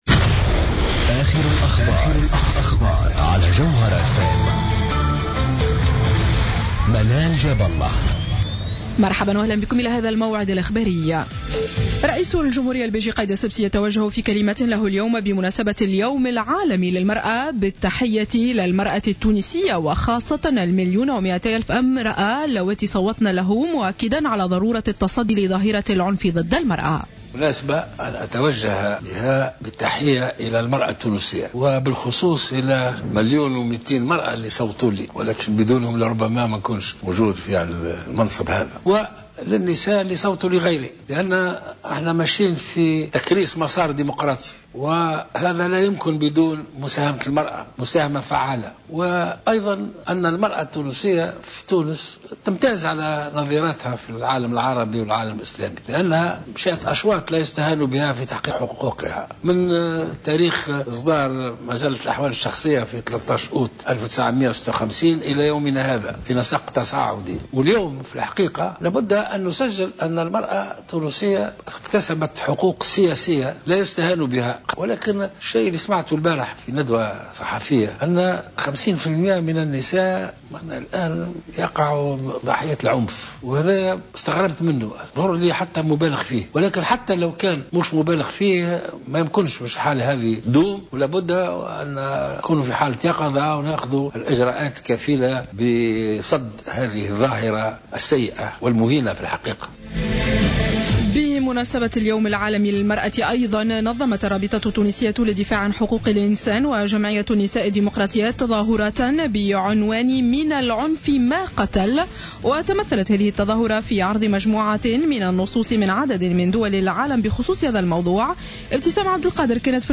نشرة أخبار السابعة مساء ليوم الأحد 08 مارس 2015